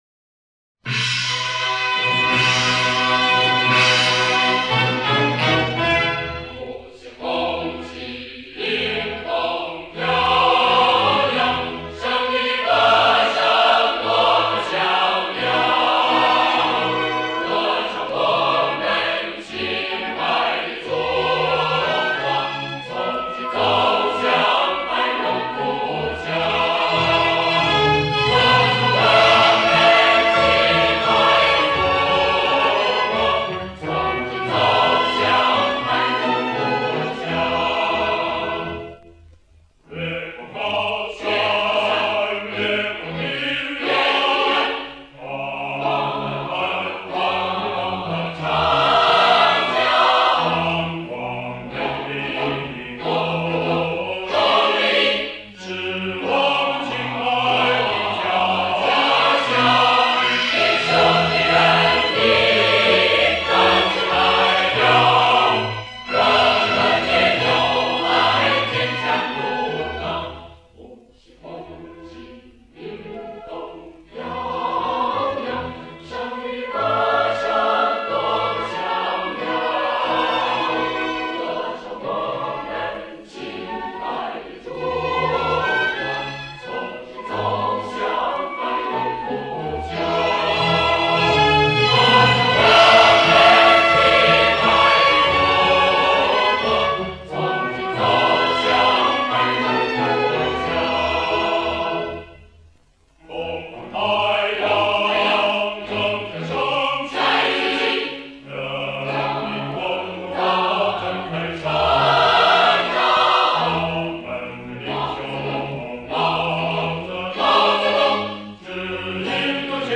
和声编配与我们熟悉的版本都不太一样